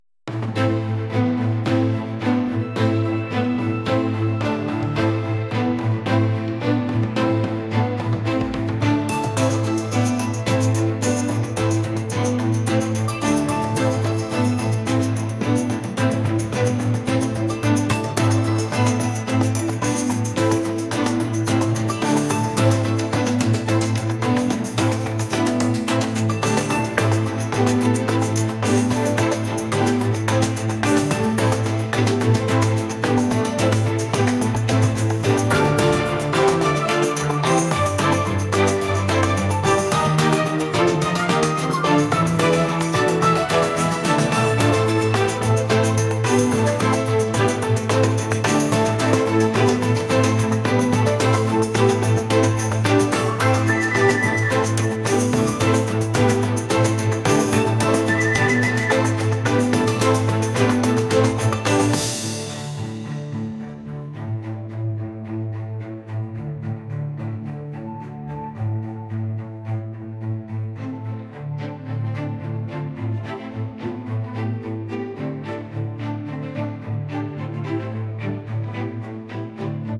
不気味